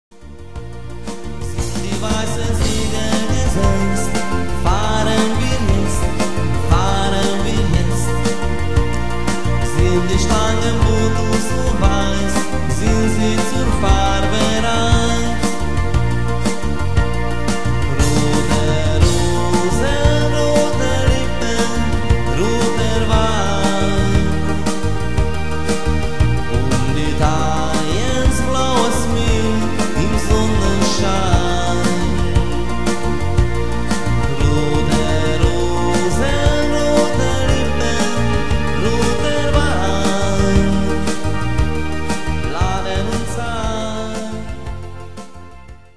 Programm mit meinem Gesang